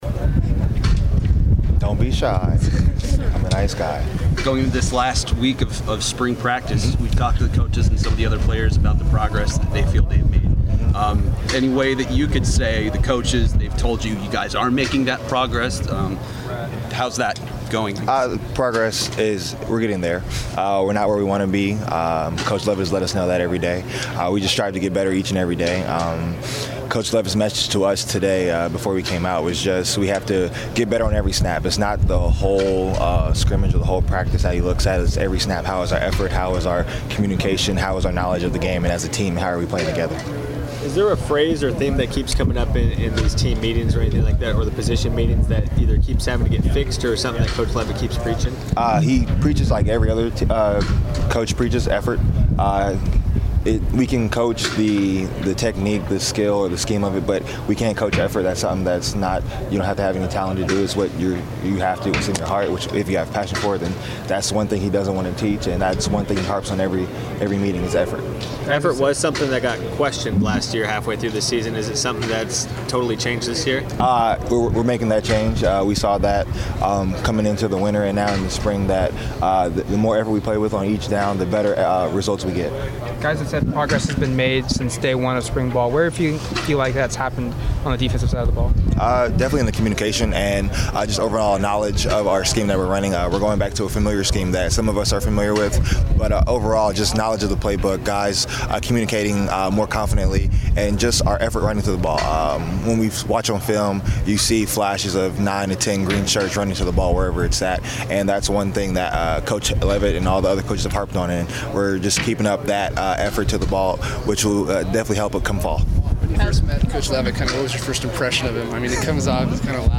speaks with the media before Oregon's scrimmage.